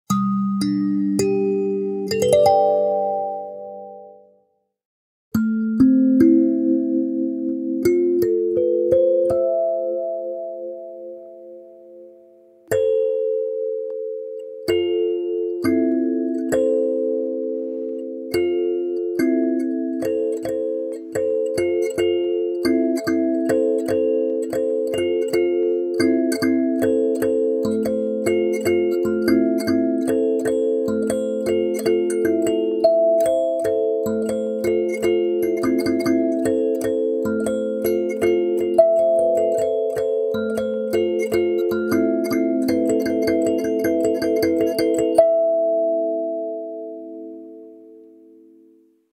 Sansula Renaissance • La Mineur
Grâce à sa membrane amplificatrice, elle offre des mélodies aériennes et vibrantes, créant une atmosphère magique et apaisante.
Son accordage en La Mineur permet de jouer des harmonies fluides et naturelles, parfaites pour un jeu intuitif.
• 9 lamelles accordées en La Mineur, pour une harmonie fluide et intuitive
• Membrane amplificatrice, offrant un son profond et vibratoire
• Effet sonore en modulant la pression sur la base de l’instrument
Sansula-Renaissance-La-mineur.mp3